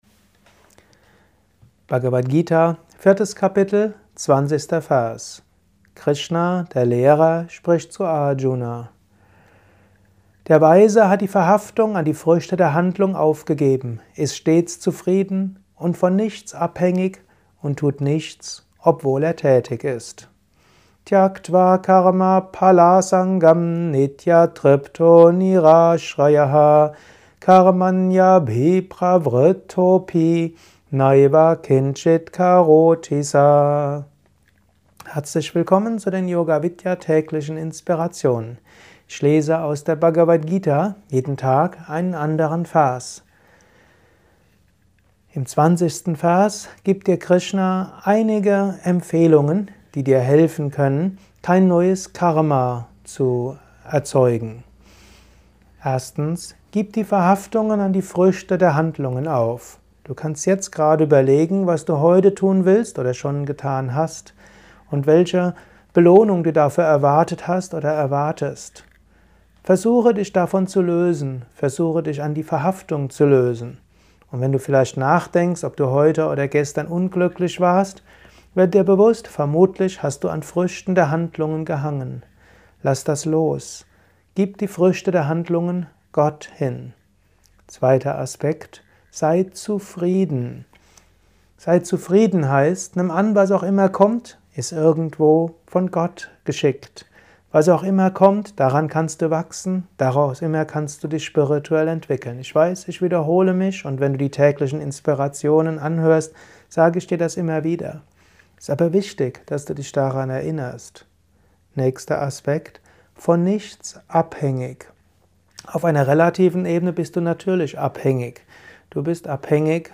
Dies ist ein kurzer Kommentar als Inspiration für den heutigen
Aufnahme speziell für diesen Podcast.